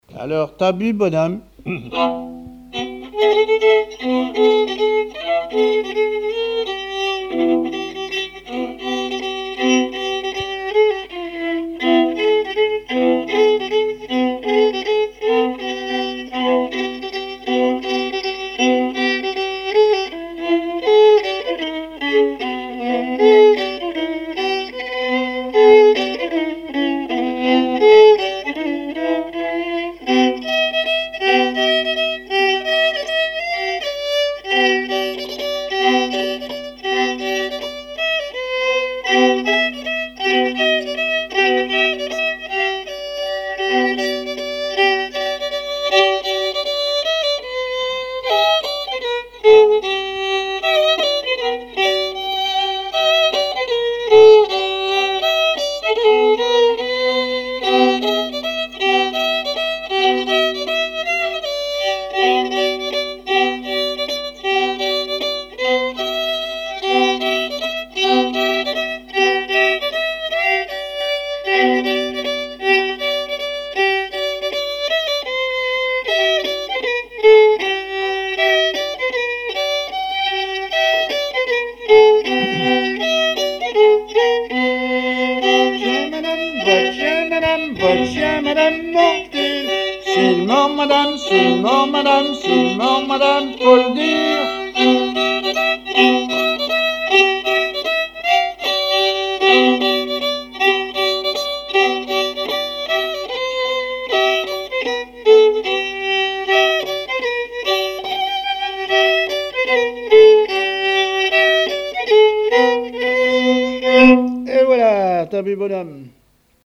Laforte : Votre petit chien madame - V, F-159 Coirault : non-référencée - 141** Thème : 1074 - Chants brefs - A danser Résumé : Votre chien madame, votre chien Madame, Mord-t-il ?
danse : mazurka
Enquête Conservatoire des Musiques Anciennes et Traditionnelles de Vendée
répertoire musical au violon